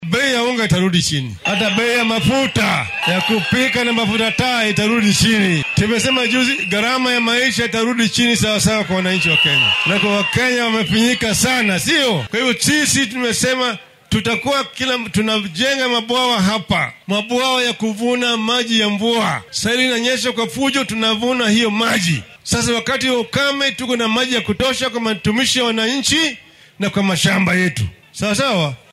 Musharraxa madaxweyne ee isbeheysiga Azimio La Umoja-One Kenya Raila Odinga ayaa shalay isku soo baxyo siyaasadeed ka sameeyay ismaamullada Machakos iyo Makueni.